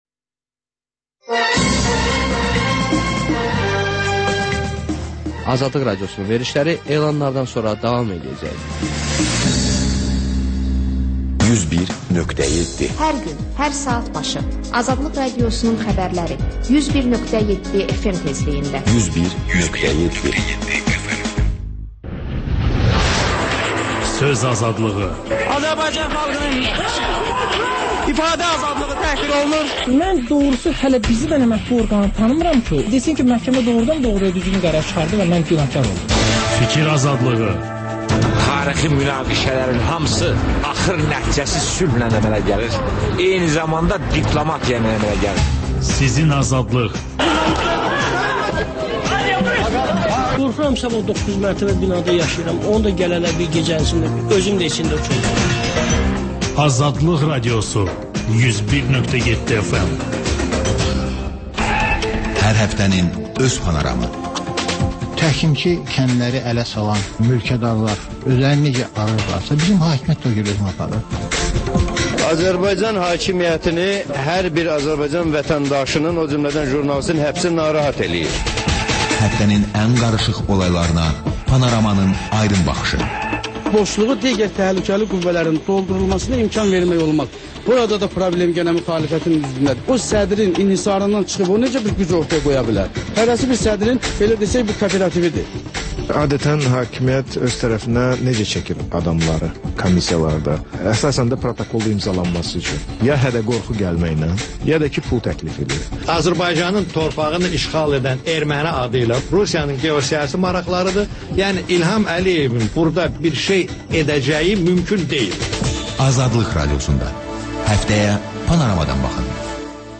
Xəbərlər, HƏMYERLİ: Xaricdə yaşayan azərbaycanlılar haqda veriliş, sonda MÜXBİR SAATI: Müxbirlərimizin həftə ərzində hazırladıqları ən yaxşı reportajlardan ibarət paket